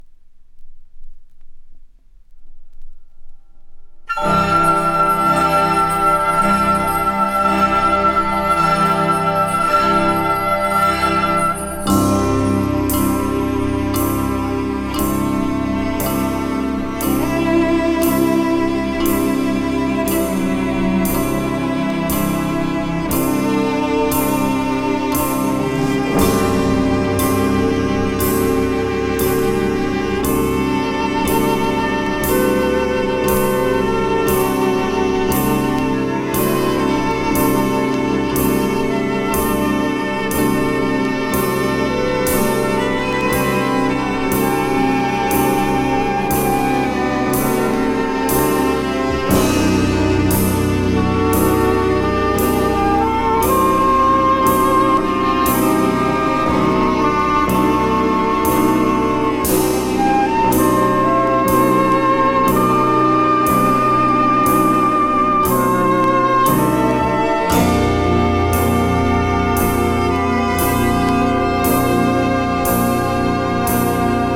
ジャズとオーケストラの融合的アルバム。